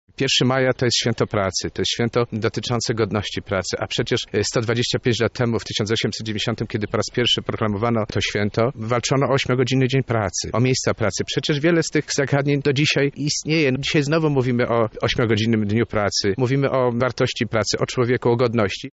Walczyli oni o godność pracy, która dzisiaj dalej jest aktualna – mówi Stanisław Kieroński, przewodniczący Rady Miejskiej z SLD w Lublinie.